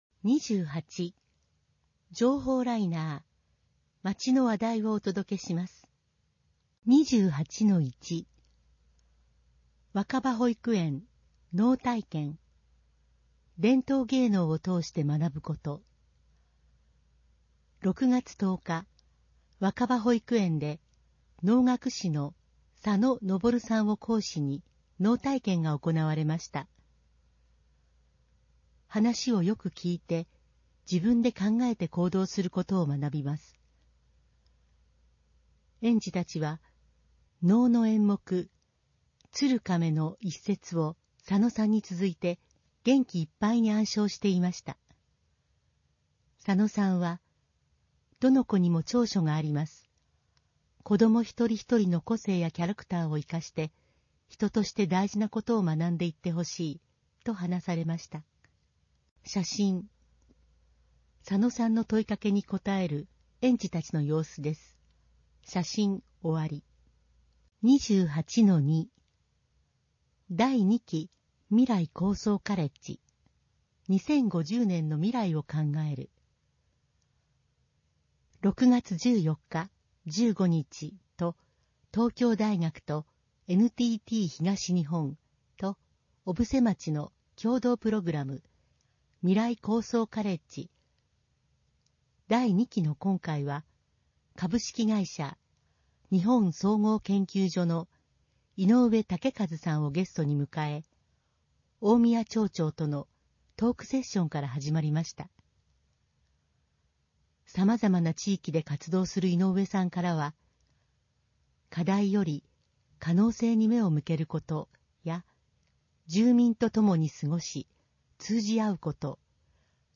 毎月発行している小布施町の広報紙「町報おぶせ」の記事を、音声でお伝えする（音訳）サービスを行っています。音訳は、ボランティアグループ そよ風の会の皆さんです。